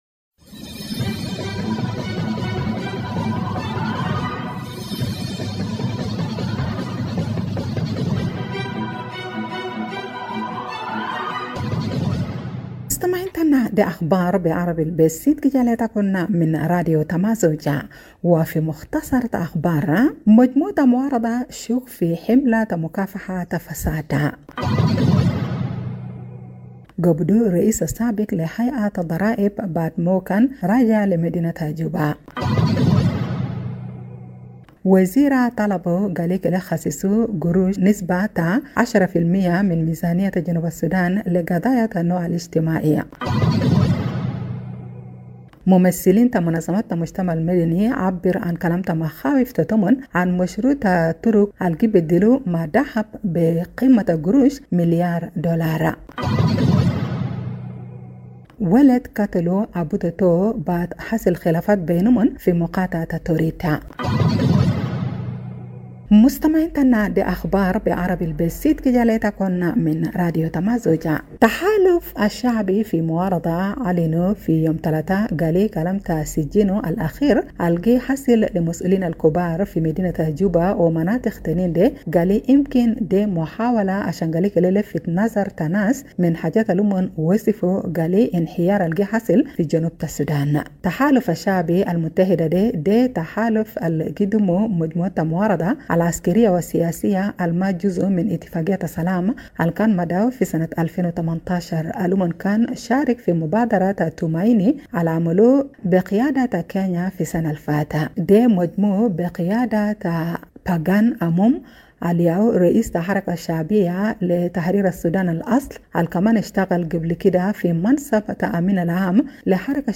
Evening Broadcast 04 March - Radio Tamazuj
Radio Tamazuj Juba Arabic News – 4 Mar 2026